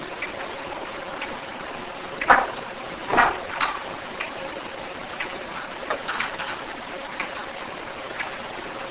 Bangs and Moving of the Recorder - This clip contains several bangs and what sounds like the recorder itself being moved slightly. This clip is not repeated and is the original.
The dragging sound is undetermined.
bangsandmovingofrecorder.wav